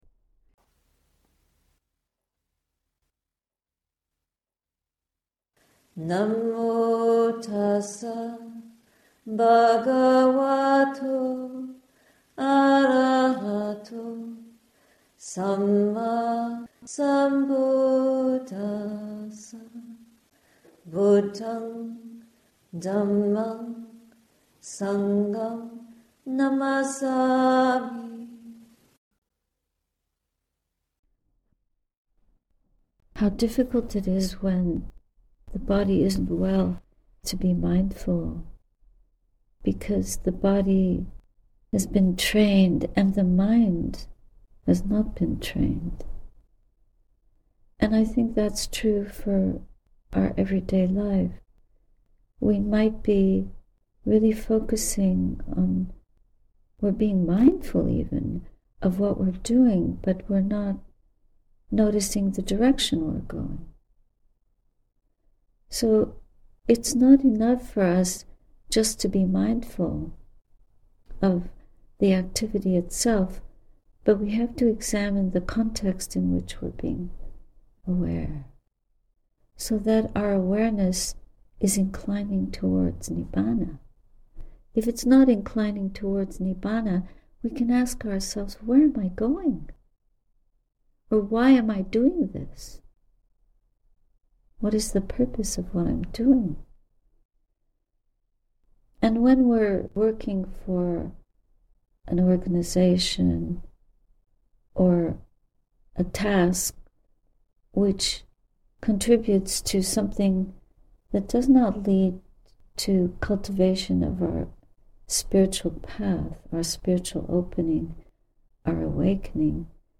Patiently, faithfully, and gently, navigate the way to true peace, unconditional love, and compassion. Morning reflections at Sati Saraniya Hermitage, Aug 7, 2024